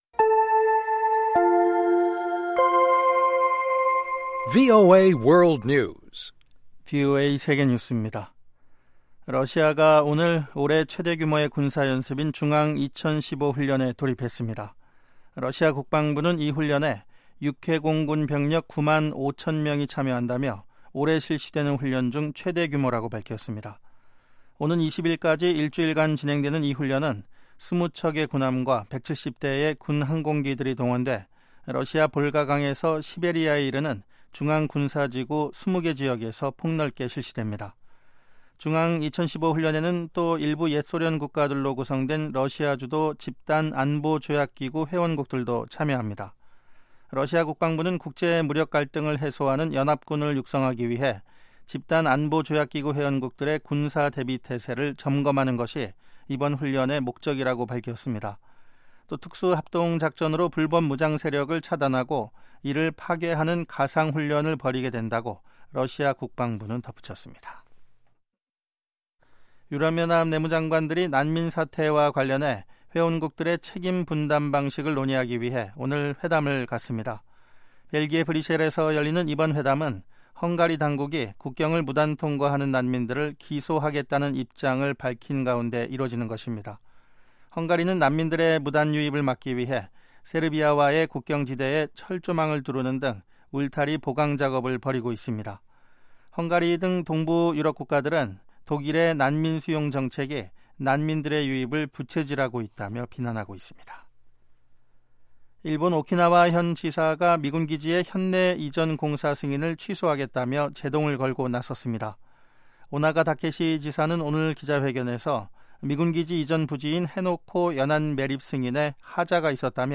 VOA 한국어 방송의 간판 뉴스 프로그램 '뉴스 투데이' 3부입니다. 한반도 시간 매일 오후 11시부터 자정까지 방송됩니다.